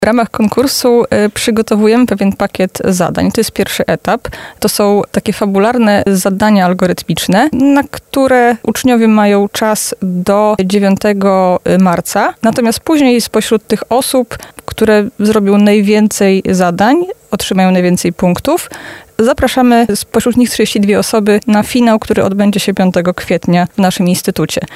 Za nami Poranna Rozmowa Radia Centrum.